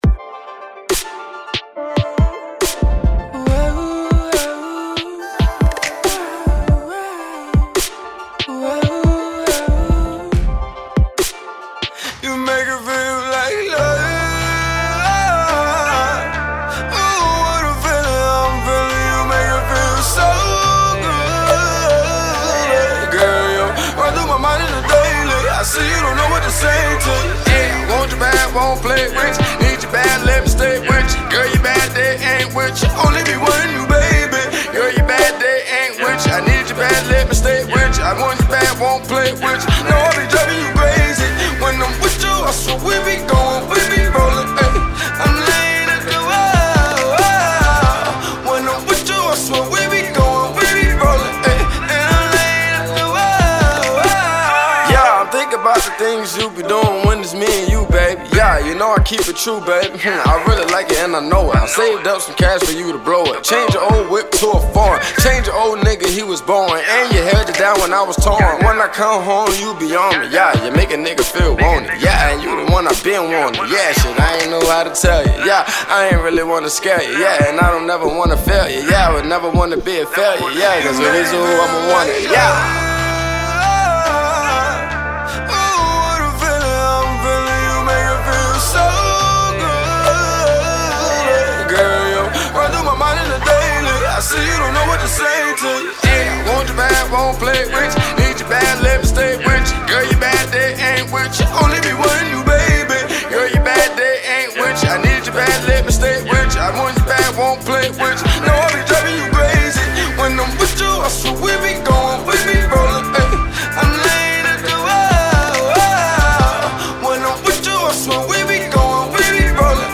HipHop/Rnb